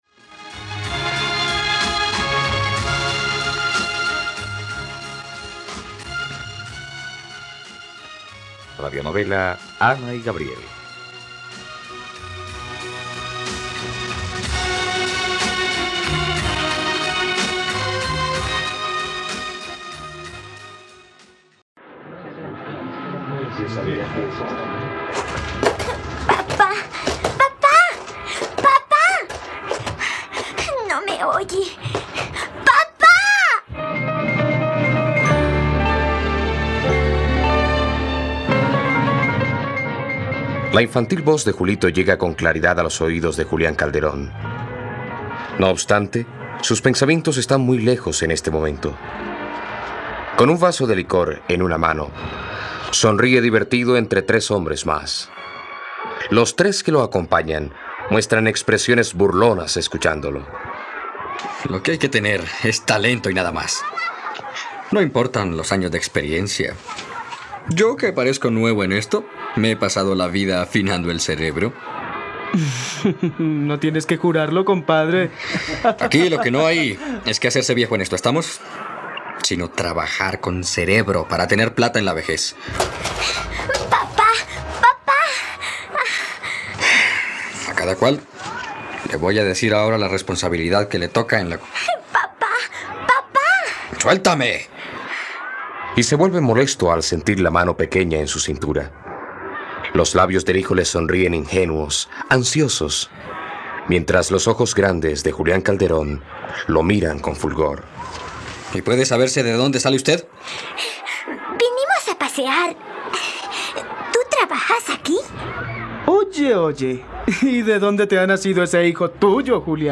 Ana y Gabriel - Radionovela, capítulo 25 | RTVCPlay